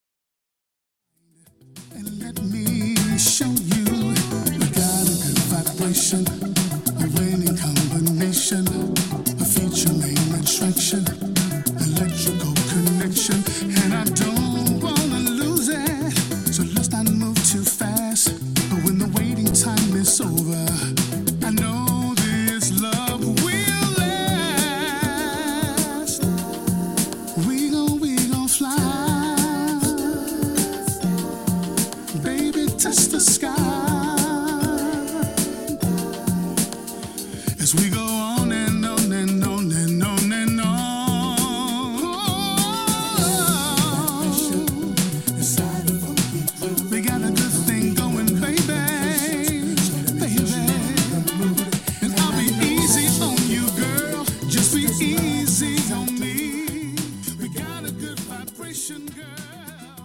rich and mellifluous voice
sophisticated production values